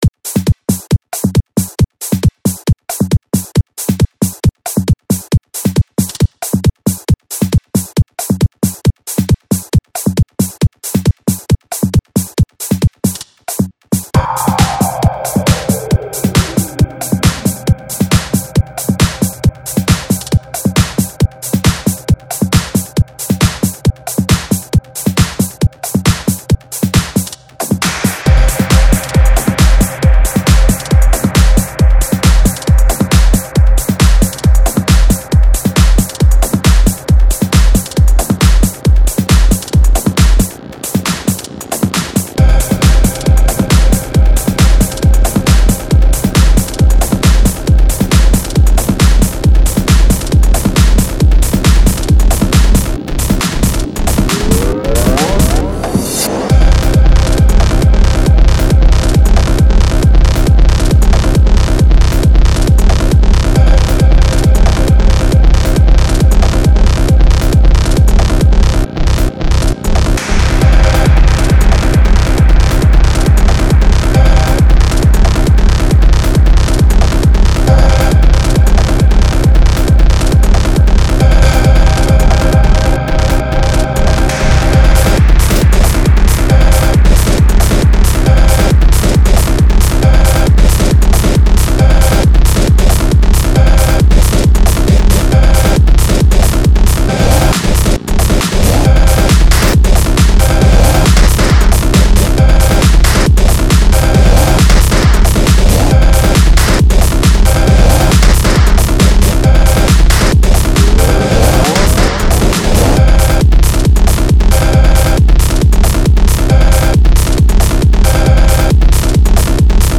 Демо (preview)-версия трека